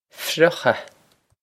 Friochadh fruhk-ah
Pronunciation for how to say
This is an approximate phonetic pronunciation of the phrase.